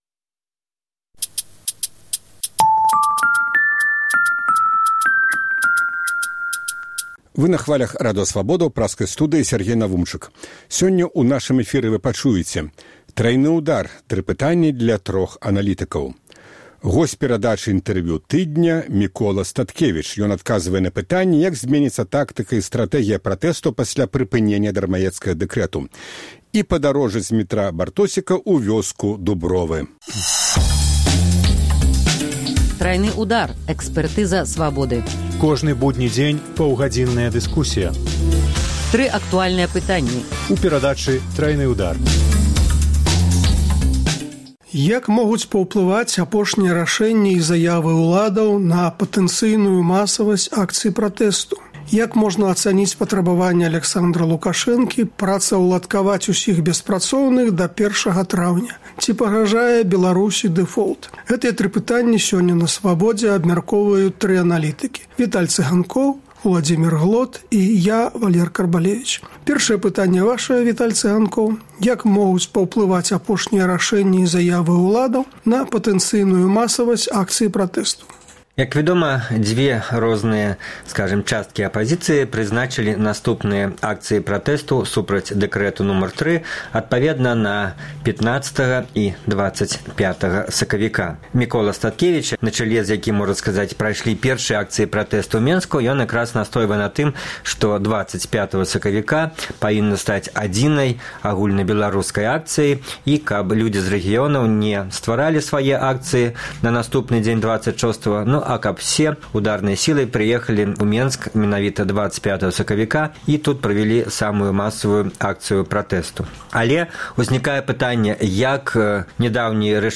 Тры пытаньні для трох аналітыкаў.